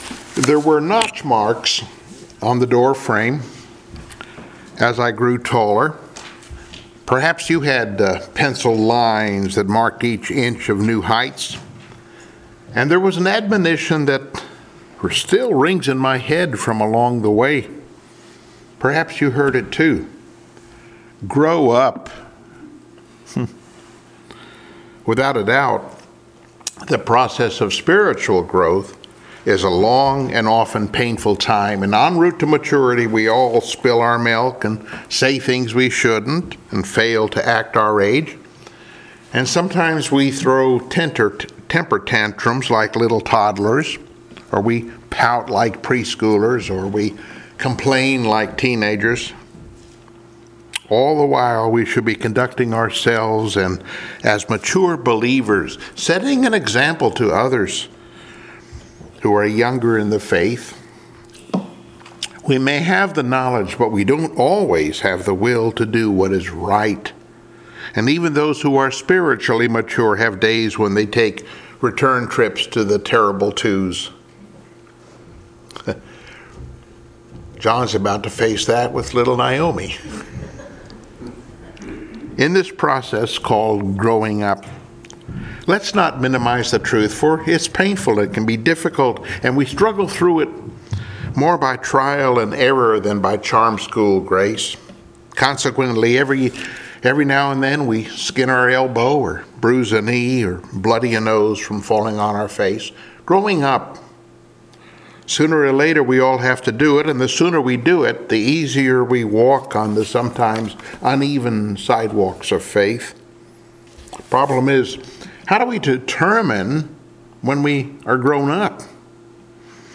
Passage: I Peter 3:8-12 Service Type: Sunday Morning Worship